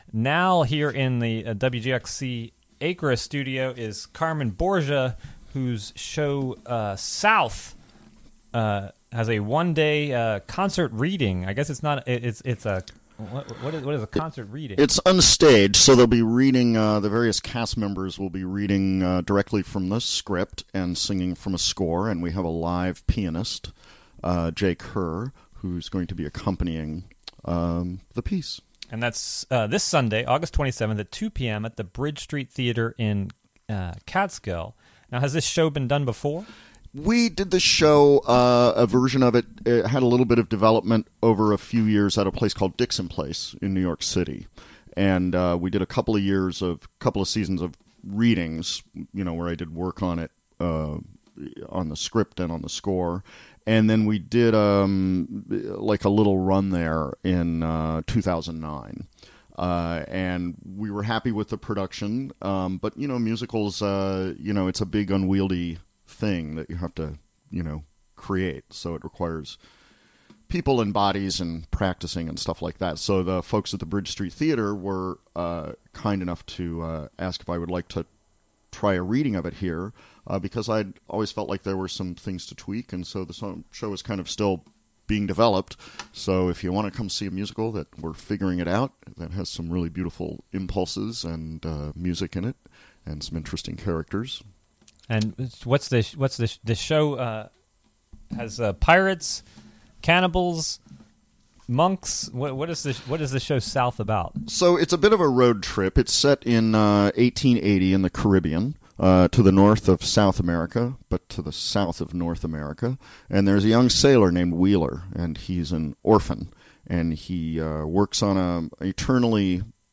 Recorded during the WGXC Morning Show of Fri., Aug. 25, 2017.